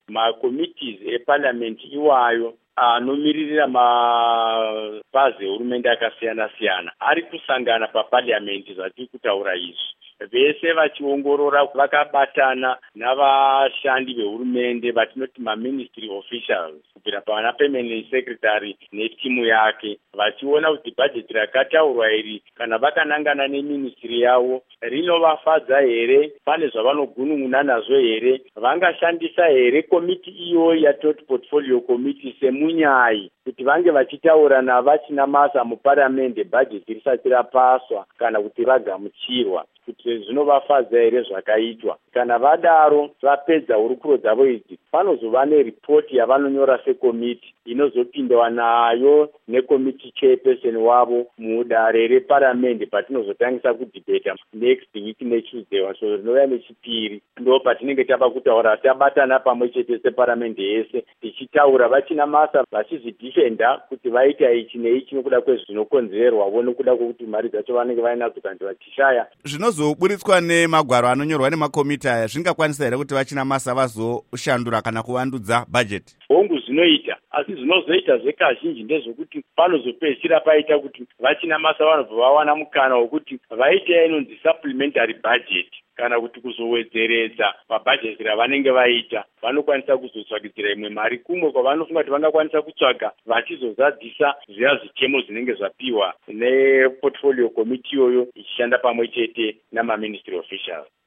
Embed share Hurukuro naVaJoram Gumbo by VOA Embed share The code has been copied to your clipboard.